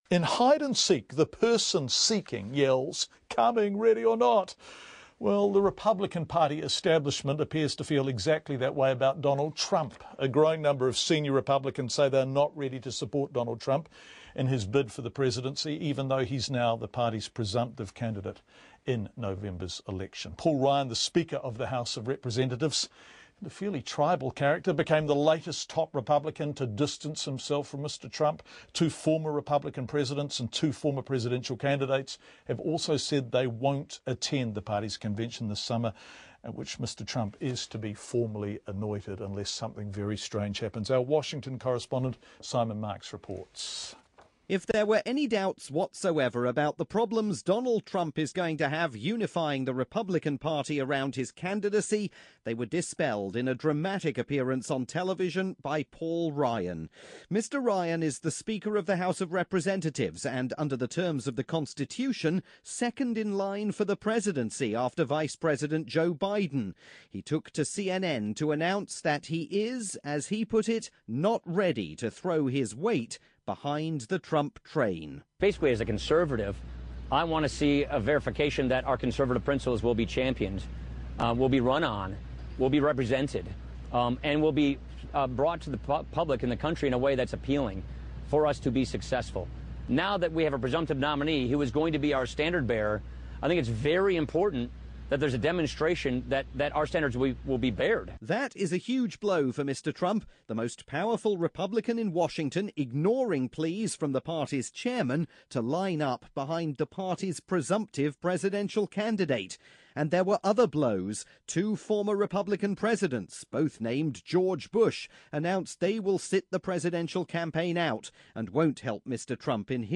report aired on Radio New Zealand's "Checkpoint" programme.